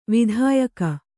♪ vidhāyaka